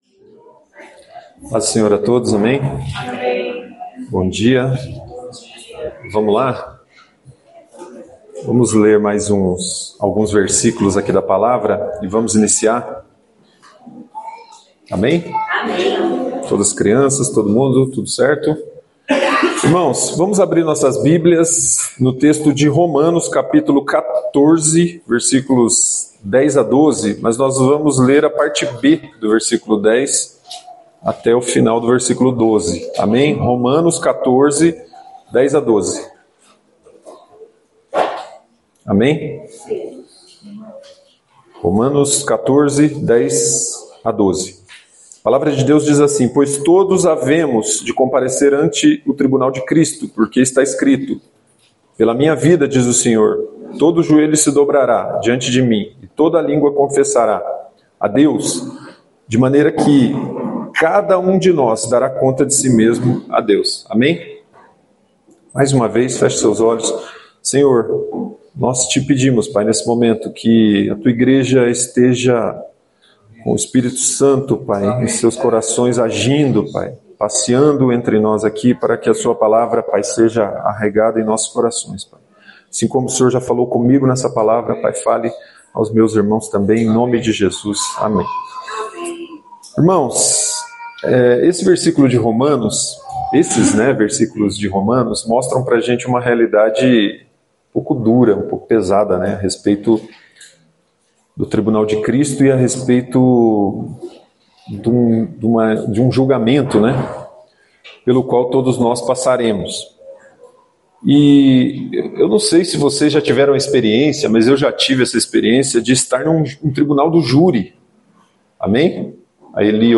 no culto do dia 31/08/2025 – Tema: Os 7 papéis de Cristo no tribunal celestial